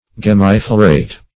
Search Result for " gemmiflorate" : The Collaborative International Dictionary of English v.0.48: Gemmiflorate \Gem`mi*flo"rate\, a. [L. gemma bud + flos, floris, flower.]